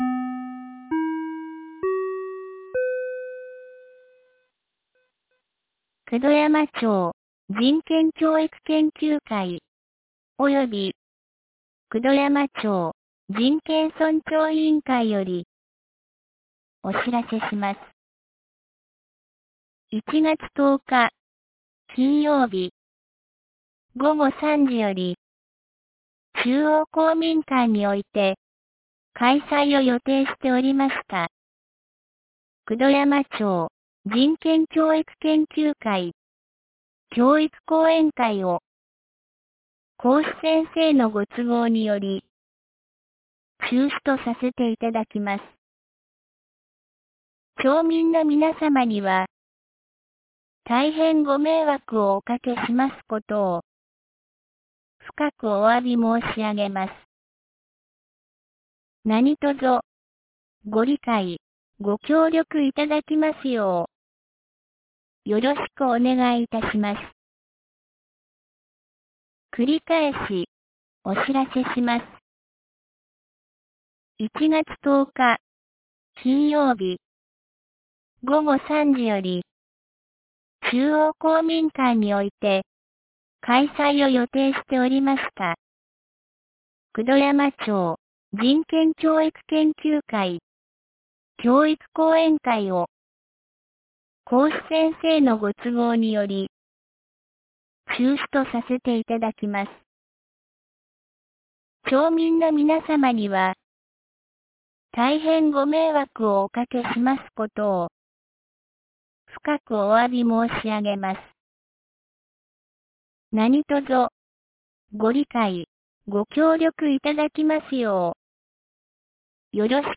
2025年01月08日 16時17分に、九度山町より全地区へ放送がありました。
放送音声